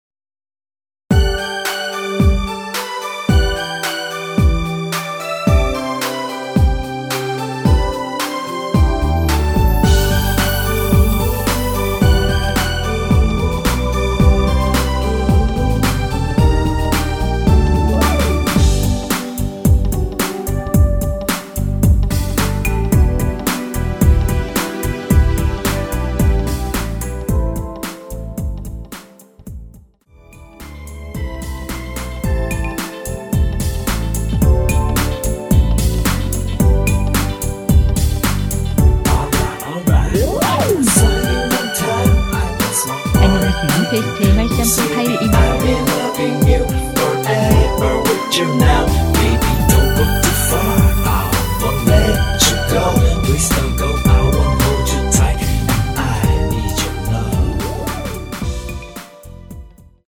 원키 랩포함된 MR입니다.
앞부분30초, 뒷부분30초씩 편집해서 올려 드리고 있습니다.
중간에 음이 끈어지고 다시 나오는 이유는